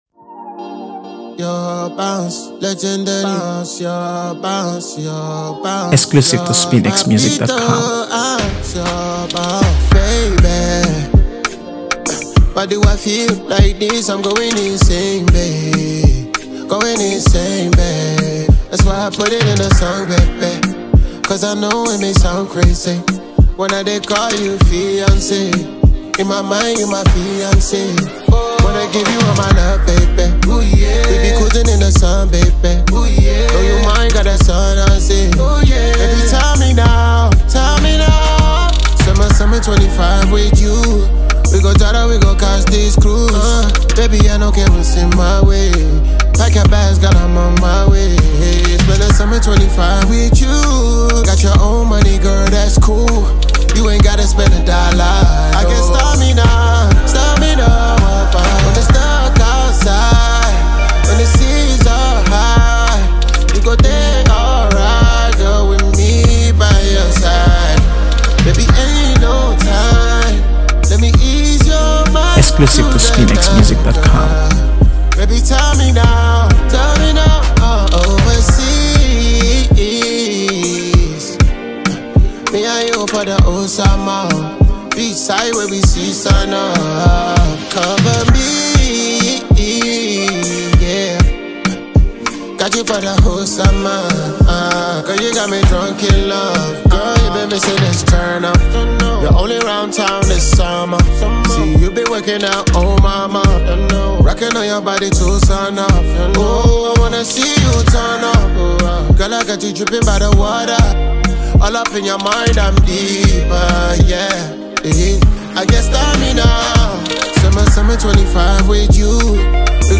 AfroBeats | AfroBeats songs
feel-good anthem that’s made for sunshine and good times.